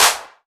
Clap
ED Claps 07.wav